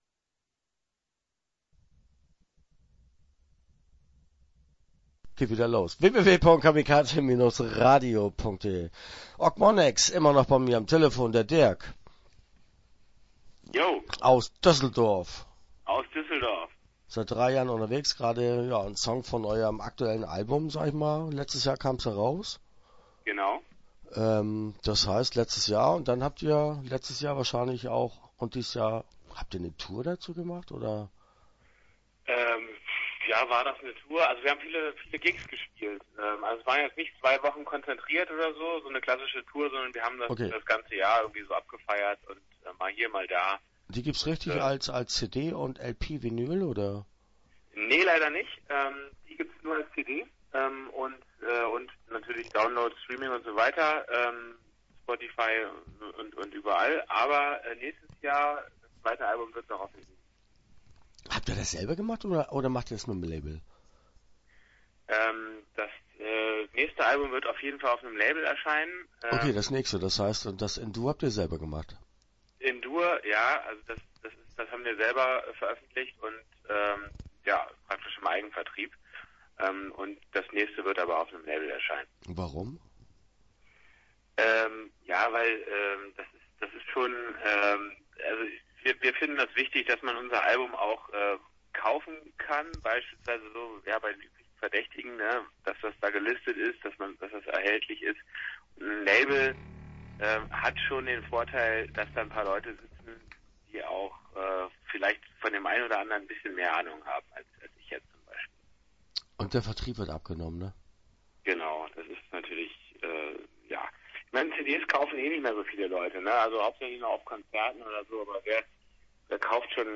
Start » Interviews » Ochmoneks